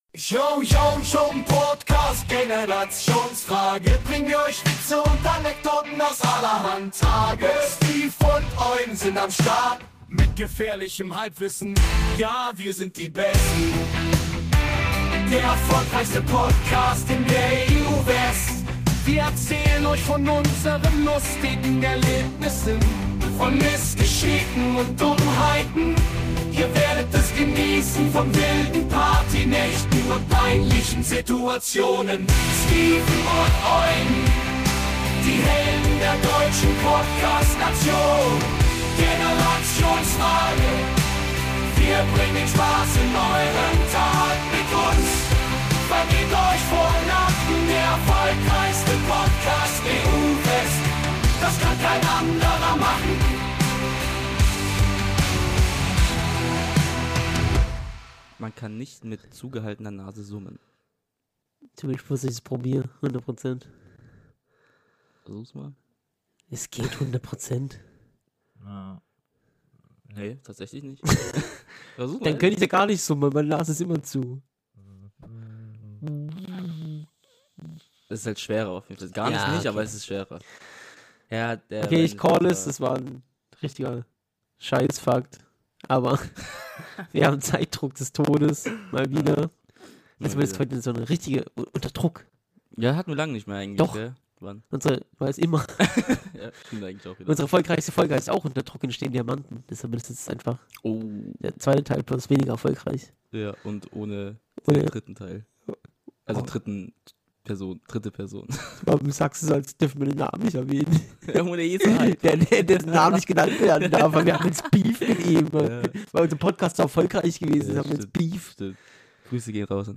Leider Gibt es die ersten 5 Minuten ein paar Tonprobleme, die verschwinden dann aber im lauf der Folge, sorry.